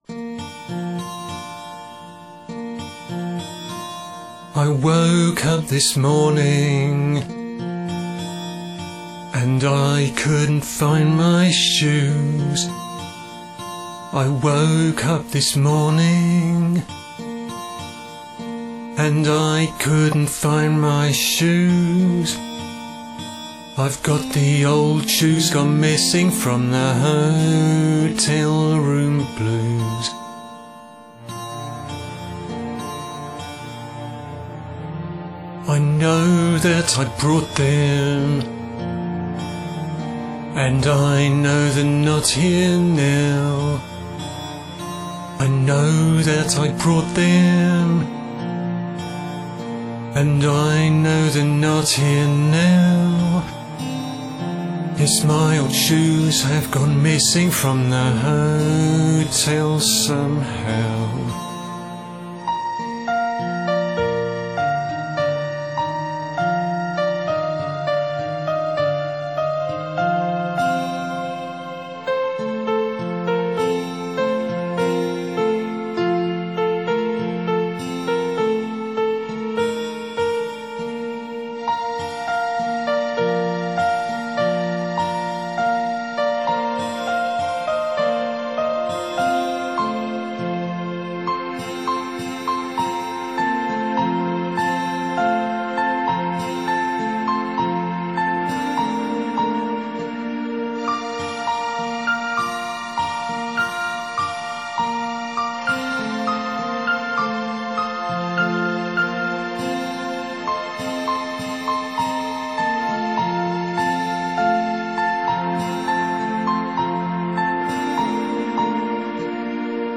I wanted to try and get a sort of early morning feel into the delivery of the lyric and the finished track at the beginning. I was very pleased with the middle 8.